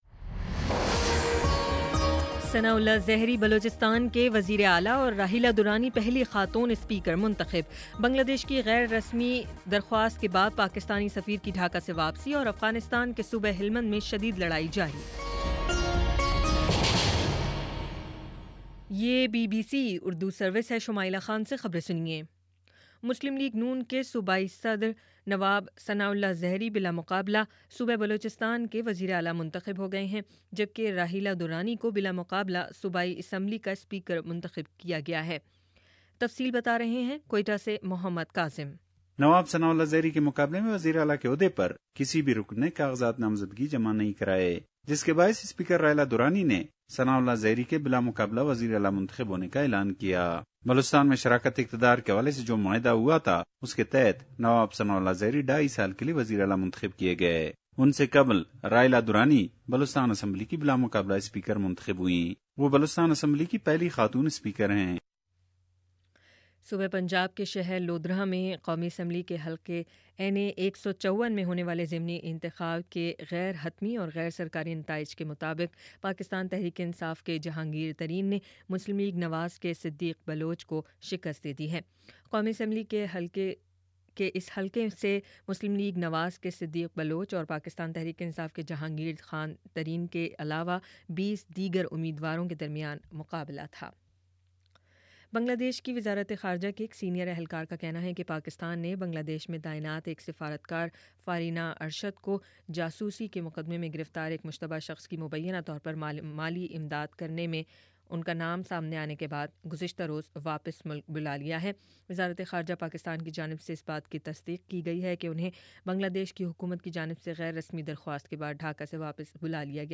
دسمبر 24 : شام پانچ بجے کا نیوز بُلیٹن